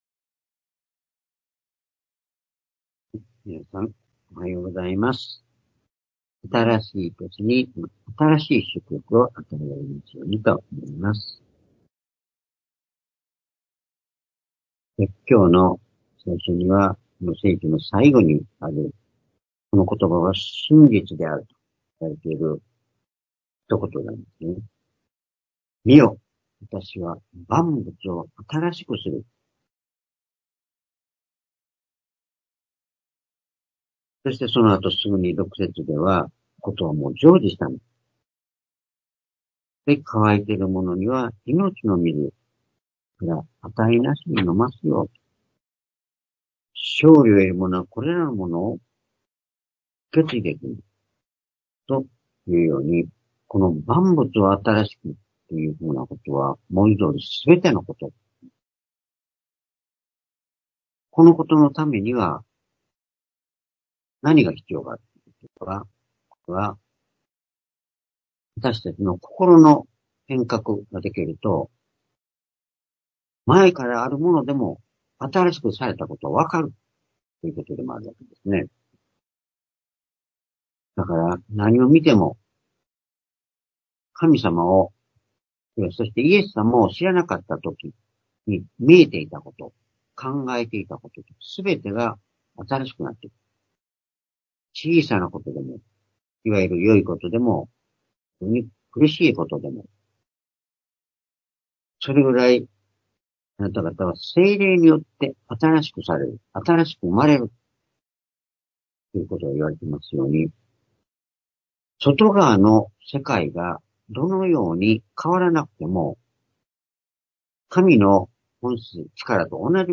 主日礼拝日時 ２０２５年1月1日（元旦礼拝） 聖書講話箇所 「主によって新しくされる」 黙示録-21-5 コリント 5-17 ※視聴できない場合は をクリックしてください。